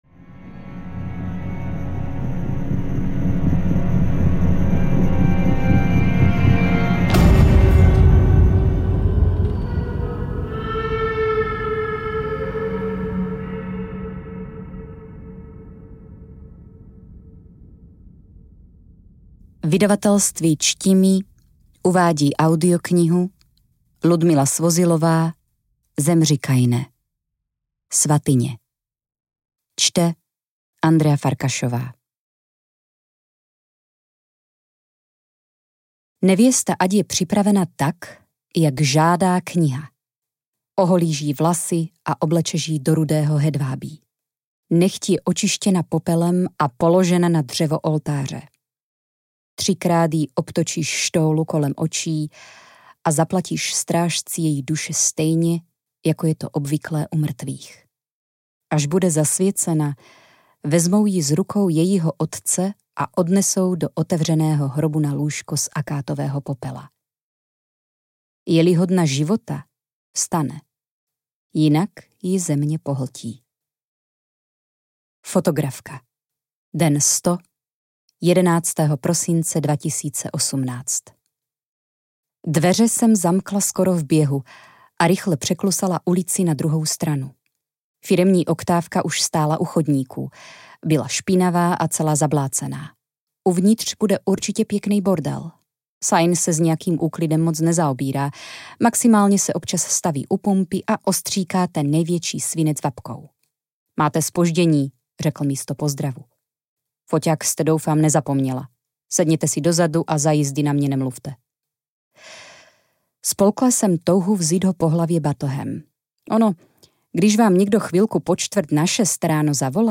Zemři, Kaine: Svatyně audiokniha
Ukázka z knihy